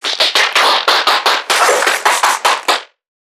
NPC_Creatures_Vocalisations_Infected [64].wav